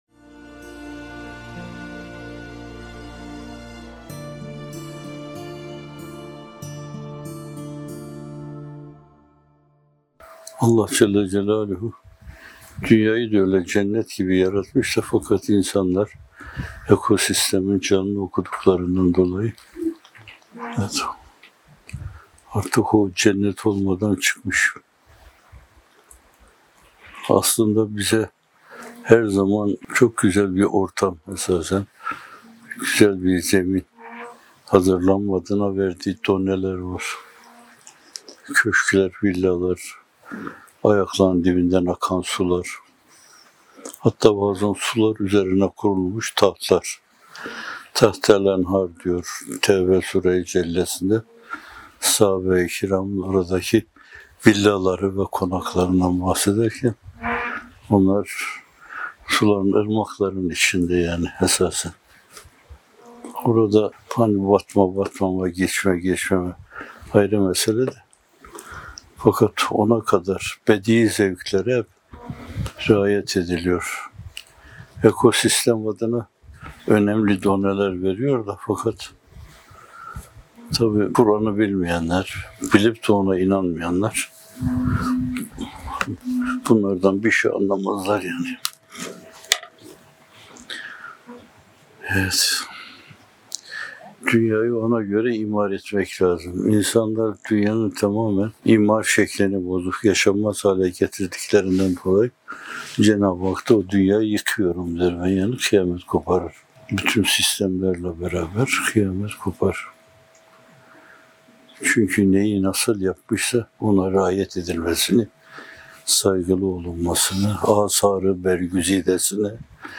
Yeni Yayın – Muhterem Fethullah Gülen Hocaefendi’nin 28 Temmuz 2020 Tarihli Sohbeti • Kur'an-ı Kerim, ekosistem adına önemli doneler veriyor.